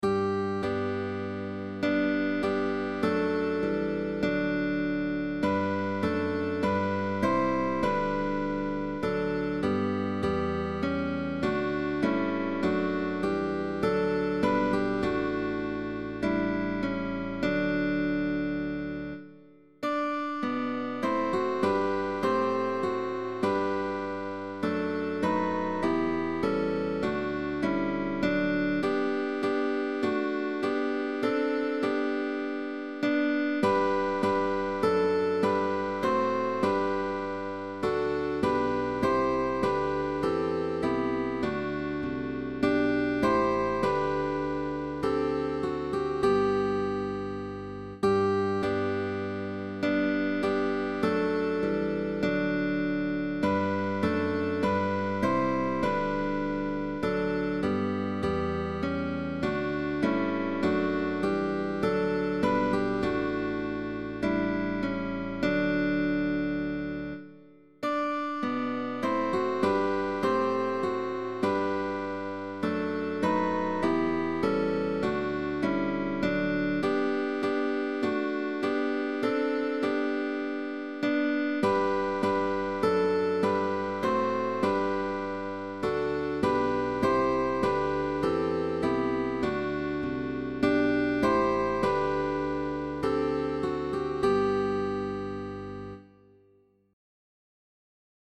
GUITAR QUARTET